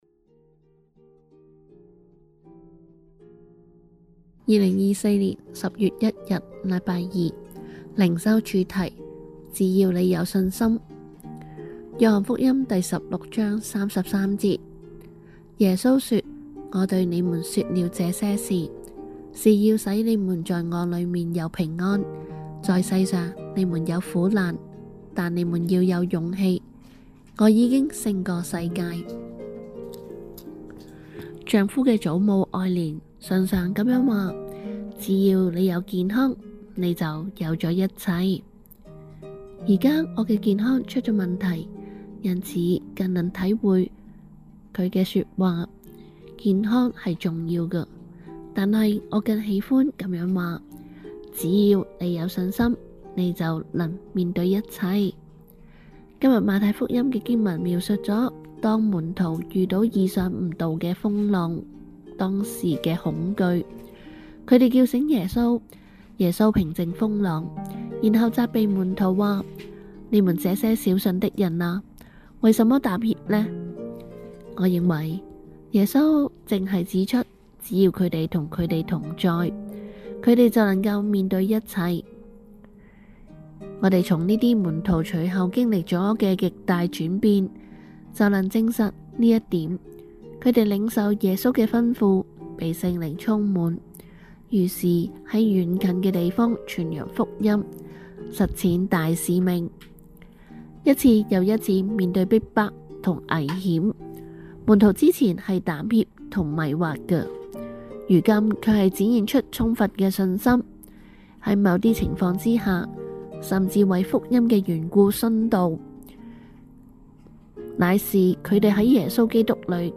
循道衞理聯合教會香港堂 · 錄音佈道組 Methodist Outreach Programme